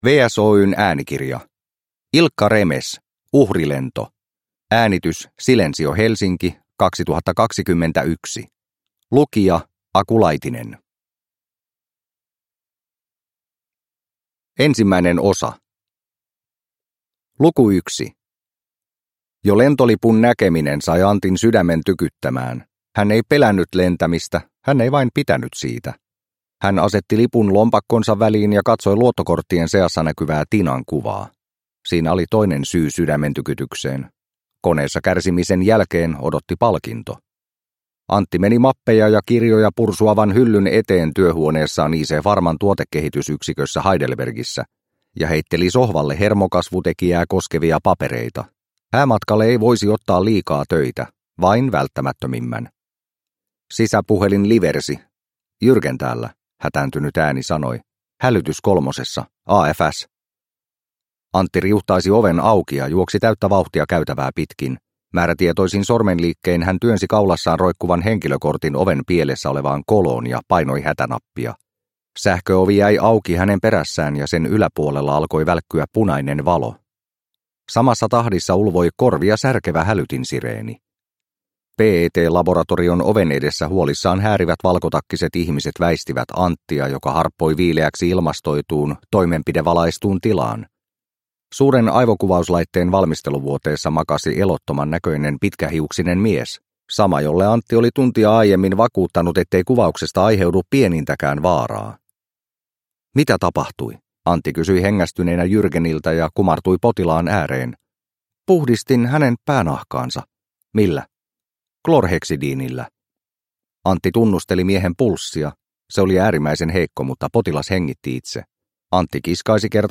Uhrilento – Ljudbok – Laddas ner